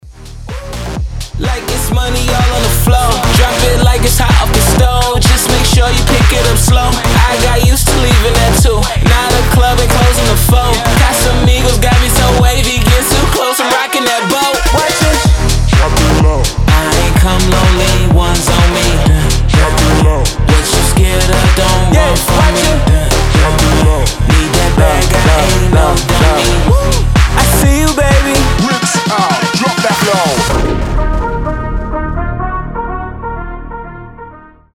• Качество: 320, Stereo
громкие
Bass House
качающие
труба